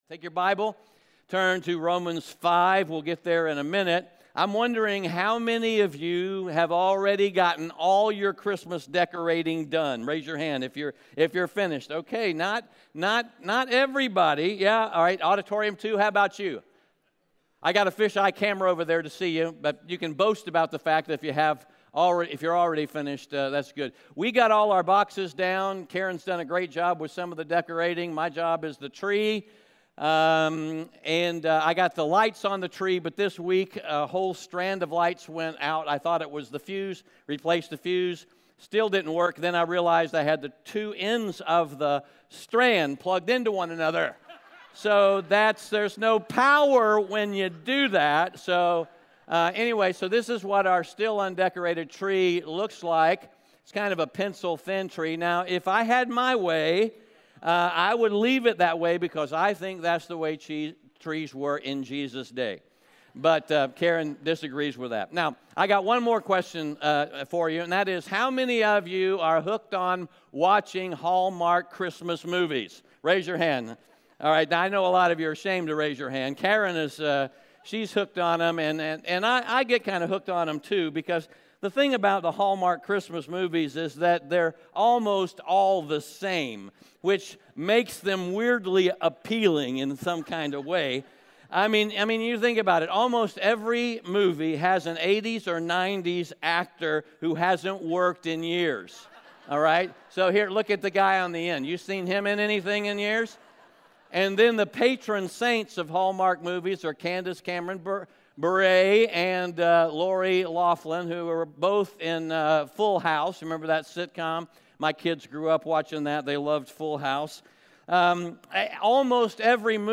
Romans 5:1-11 Audio Sermon Notes (PDF) Onscreen Notes Ask a Question *We are a church located in Greenville, South Carolina.